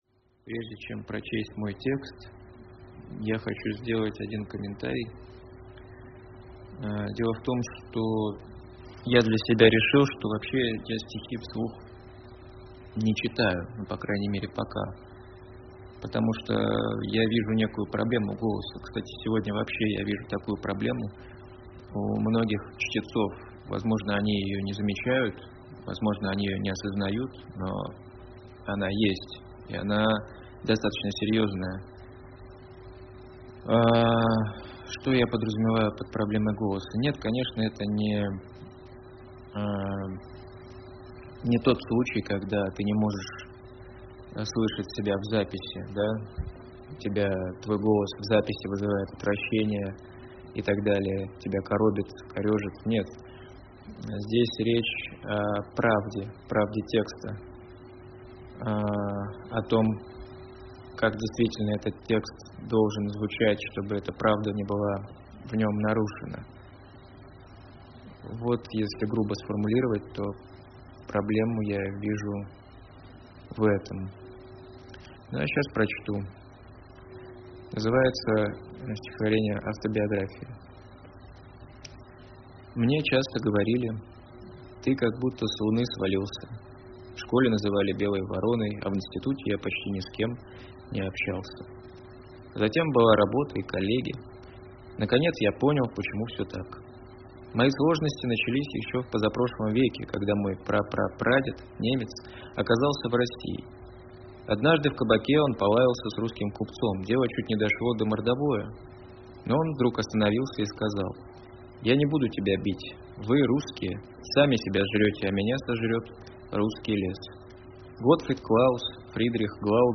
читает стихотворение «Автобиография»